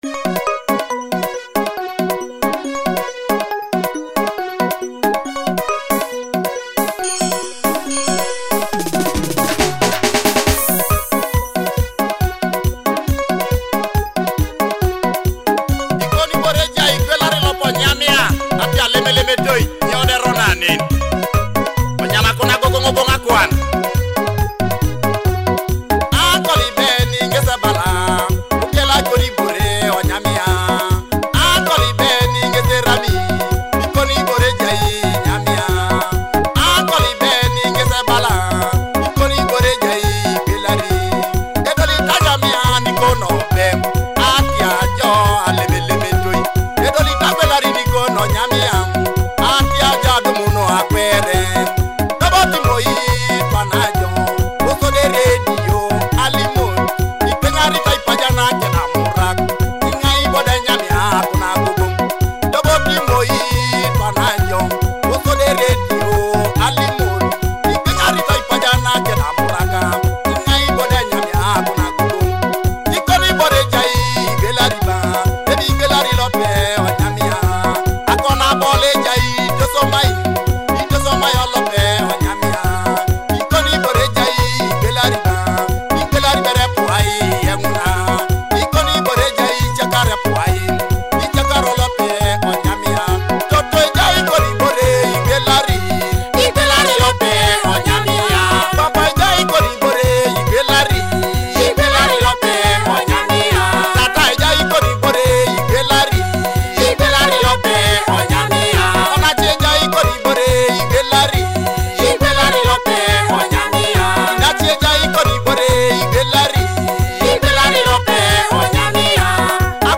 featuring joyful traditional rhythms
joyful Ateso cultural and traditional rhythms in Akogo